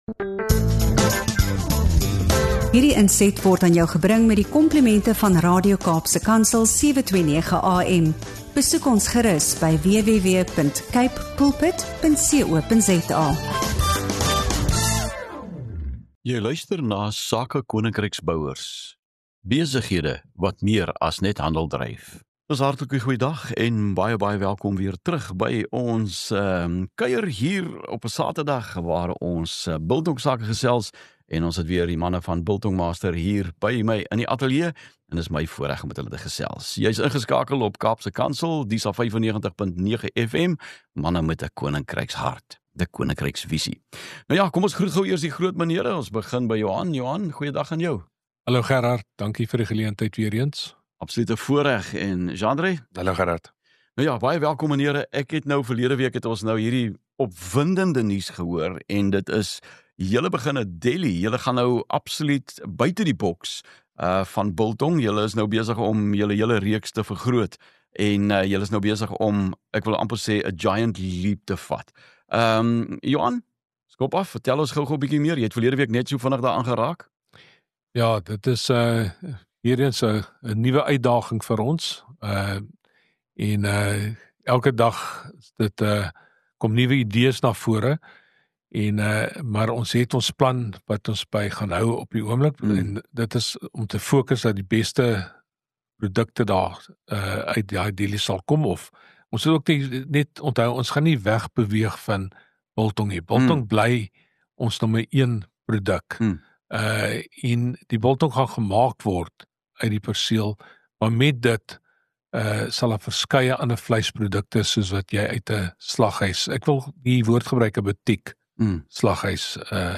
Van ambagsvleis, gerookte produkte en droëwors tot braaitoerusting en spesialiteitsitems – hierdie gesprek wys hoe geloof, besigheid en uitnemendheid saamvloei.